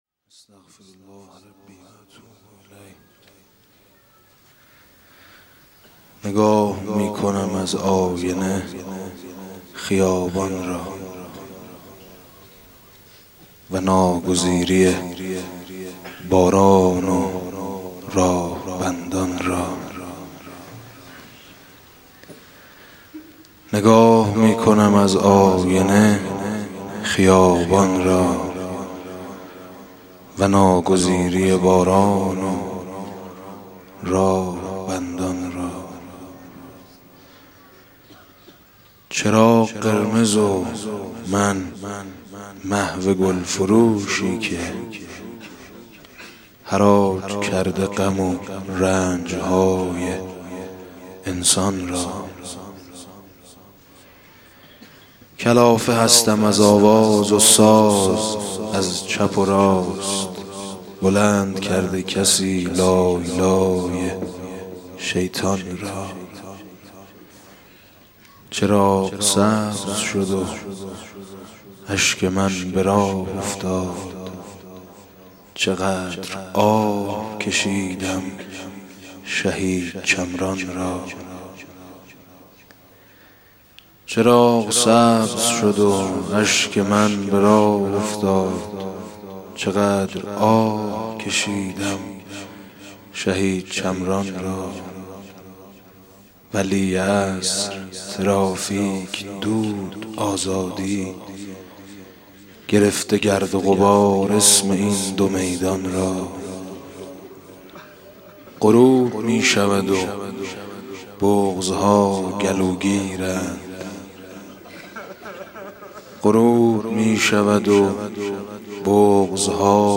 دانلود شعرخوانی میثم مطیعی با موضوعات اجتماعی + متن